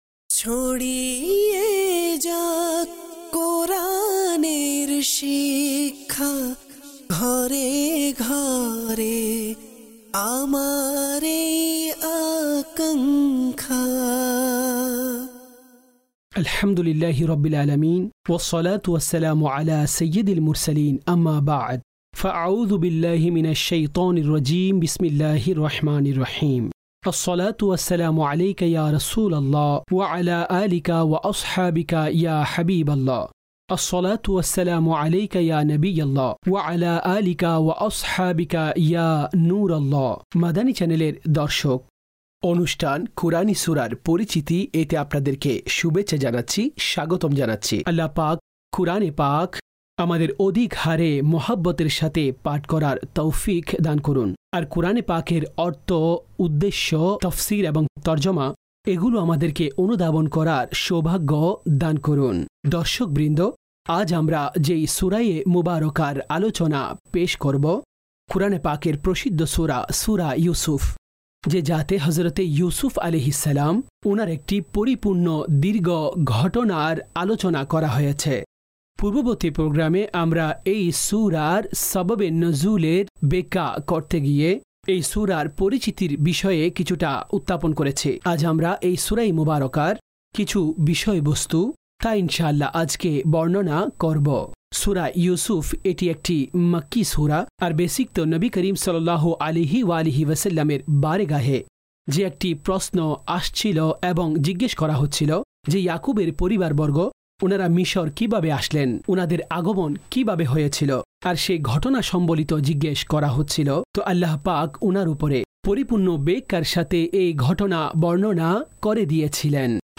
কুরআনি সূরার পরিচিতি (বাংলায় ডাবিংকৃত) EP# 24